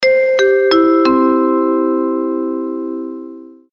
без слов
звонкие
Звуки аэропорта